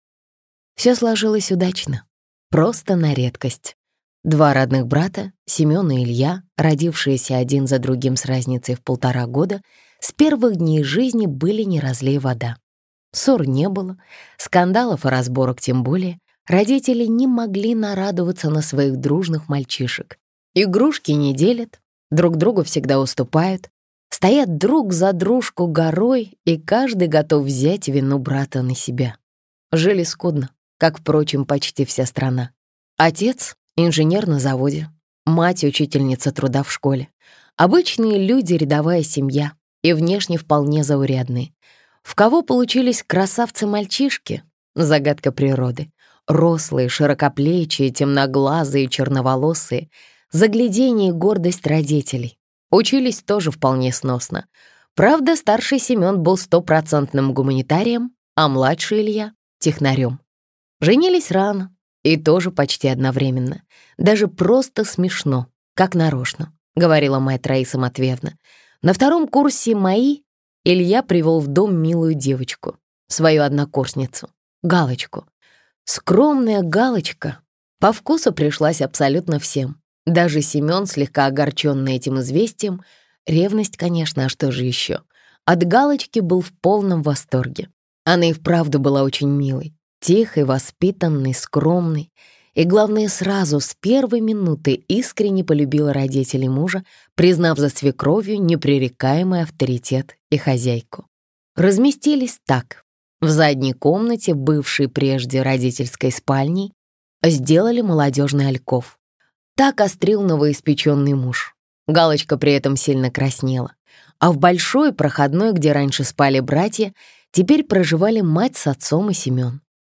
Аудиокнига Кровь не вода | Библиотека аудиокниг